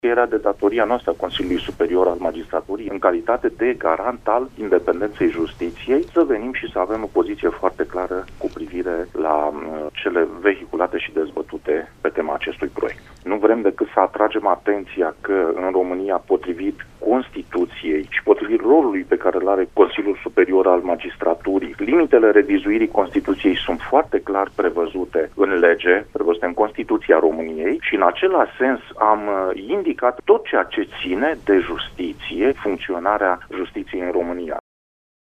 CSM a formulat reacţia la un proiect atribuit UDMR care vizează autonomia Ţinutului Secuiesc şi care conţine – în opinia membrilor Consiliului – teme care ies din cadrul constituţional. Vicepreşedintele CSM, procurorul Gheorghe Muscalu, a explicat.